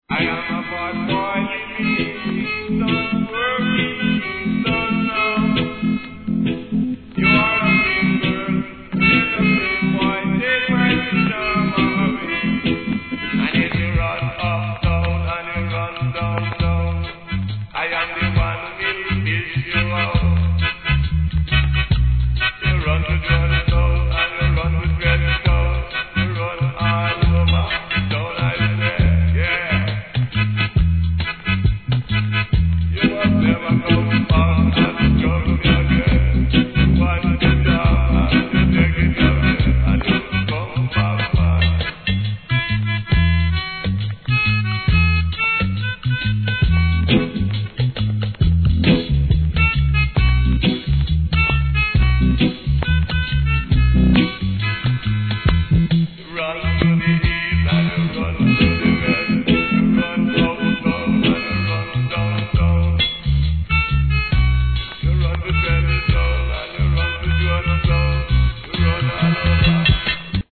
REGGAE
いなたいハーモニカのメロディーが最高です。